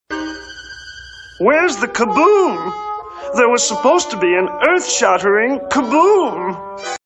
2188-earth-shattering-kaboom.mp3